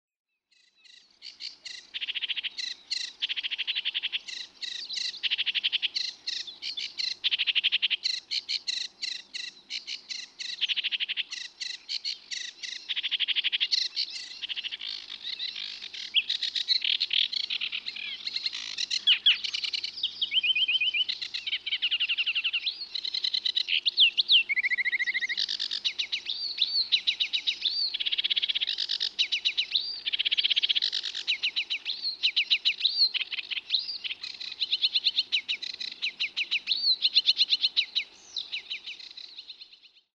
Muuttolintukalenteri: Ruokokerttunen
Ruokokerttuskoiras laulaa reviirillään lähes tauotta vuorokauden ympäri, pitäen usein vain pienen iltatauon.